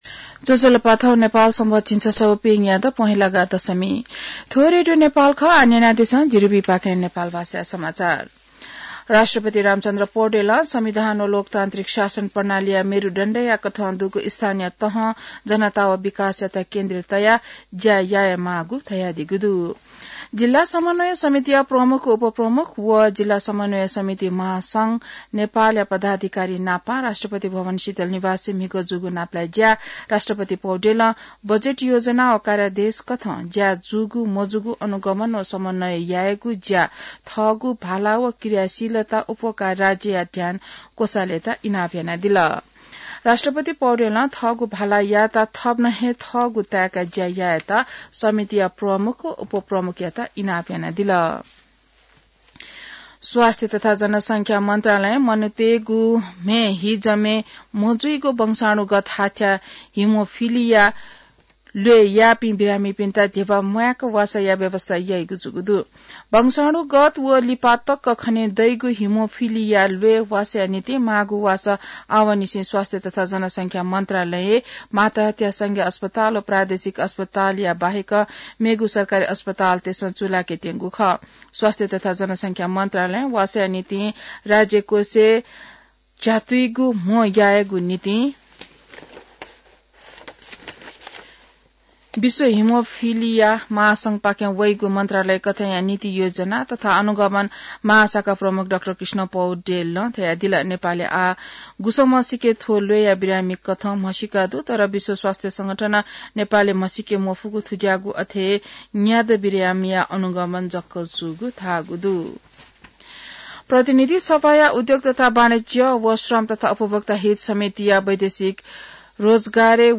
नेपाल भाषामा समाचार : १२ माघ , २०८१